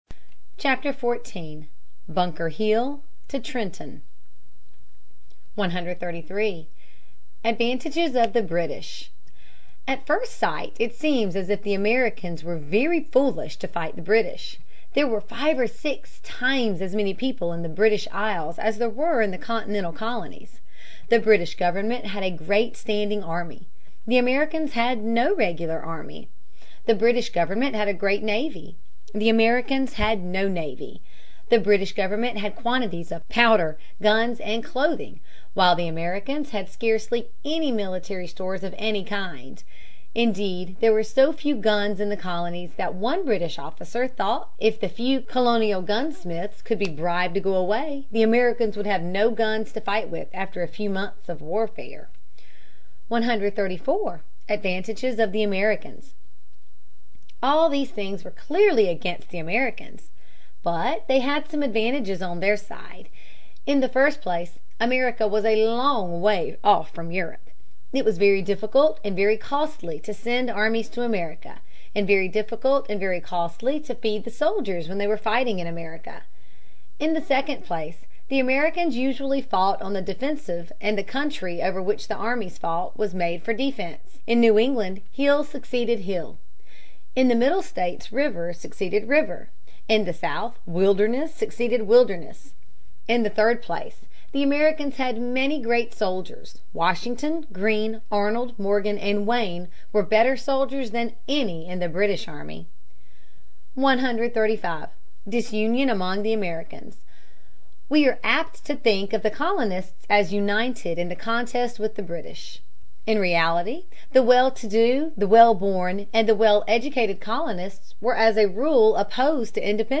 在线英语听力室美国学生历史 第44期:从邦克山到特伦顿(1)的听力文件下载,这套书是一本很好的英语读本，采用双语形式，配合英文朗读，对提升英语水平一定更有帮助。